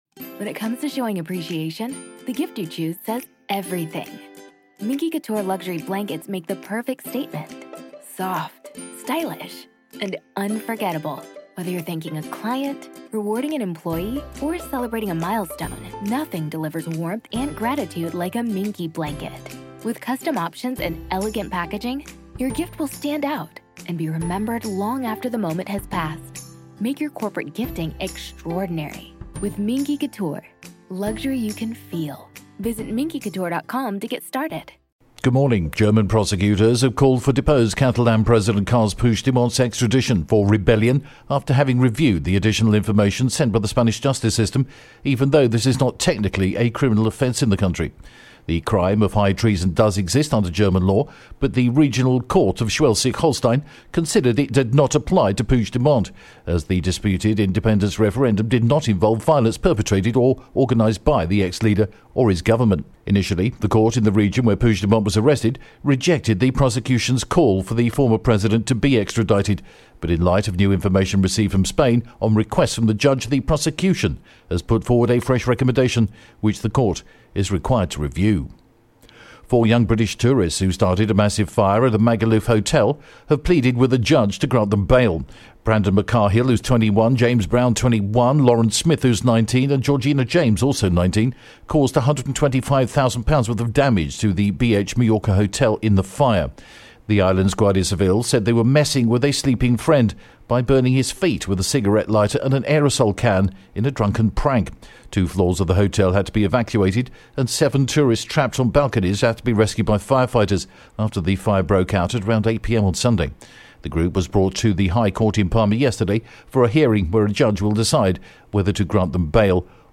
The latest Spanish News Headlines in English: May 23rd am